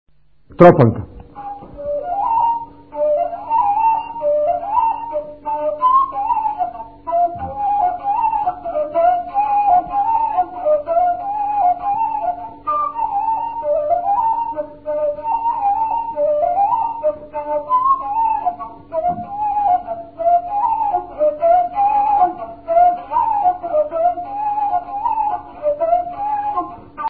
музикална класификация Инструментал
тематика Хороводна (инструментал)
размер Две четвърти
фактура Едногласна
начин на изпълнение Солово изпълнение на кавал
фолклорна област Североизточна България
начин на записване Магнетофонна лента